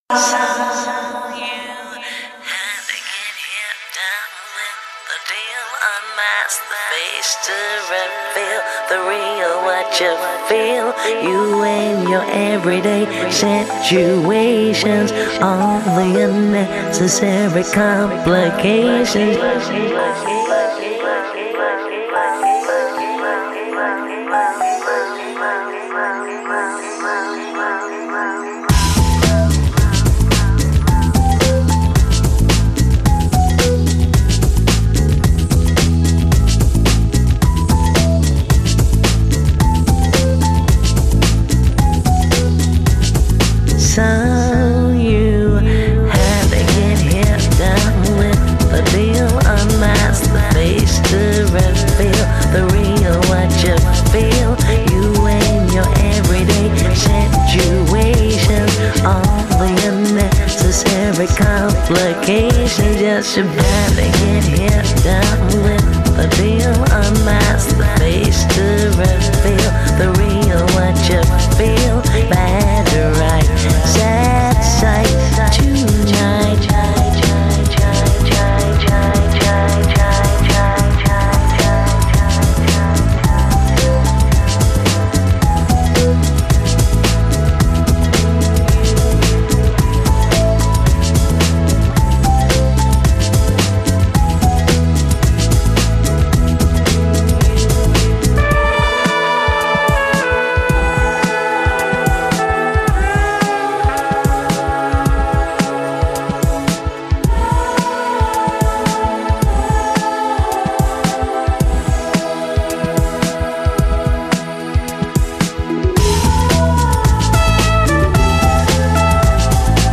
专辑类型：Lounge